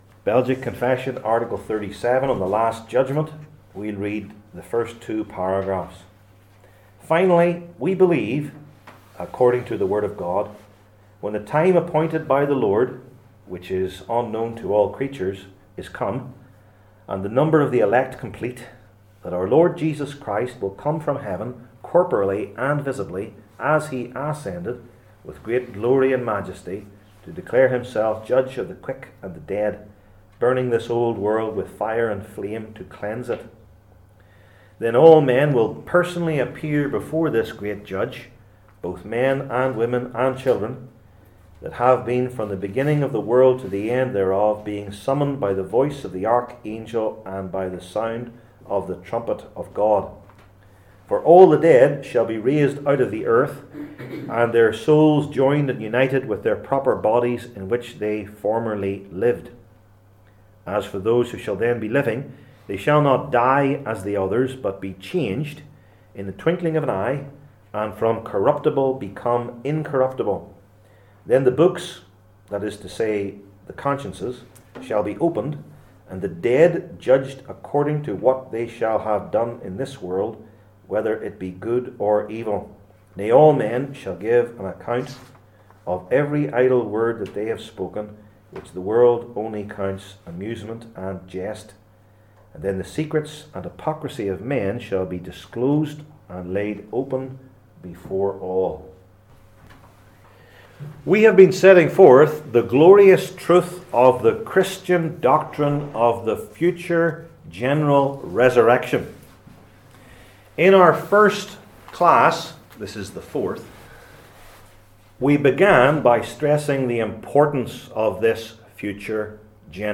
Passage: Romans 1:18-32 Service Type: Belgic Confession Classes